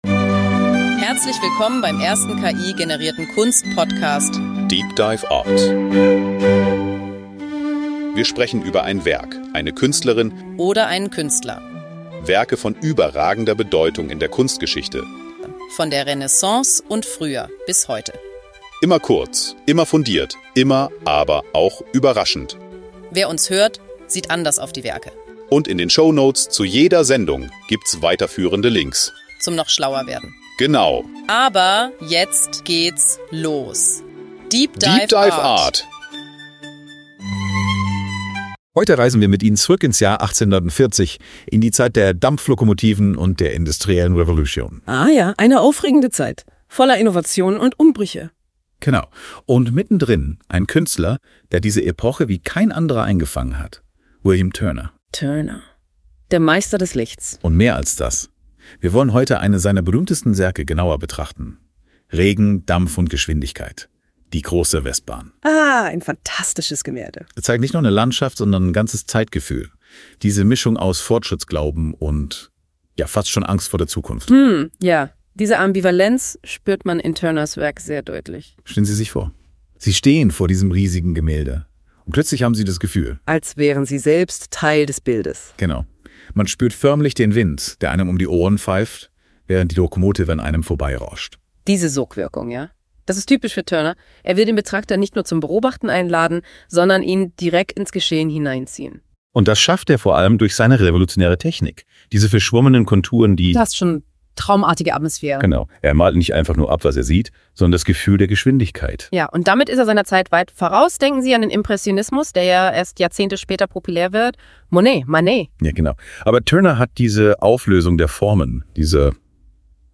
Die Gesprächspartner reflektieren
voll-ki-generierte Kunst-Podcast.